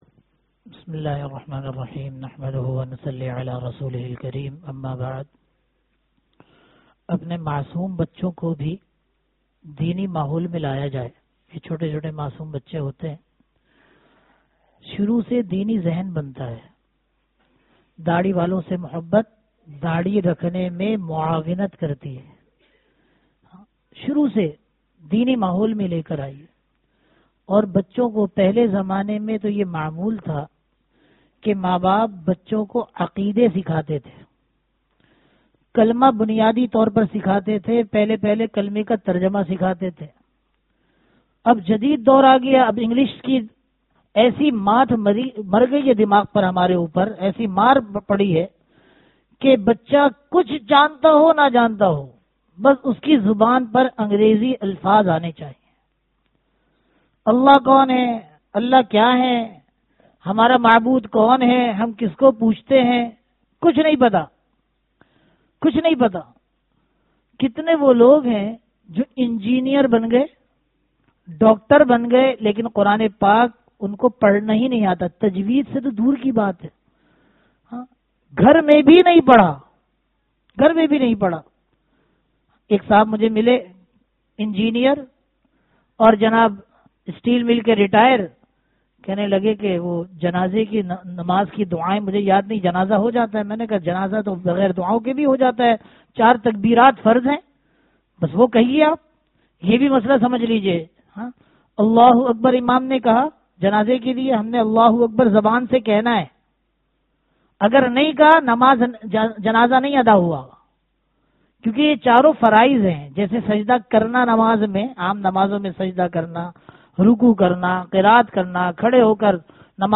Bayanat
Apne bachon ko allah walon ke pas le kar jaen (bad jummah byan)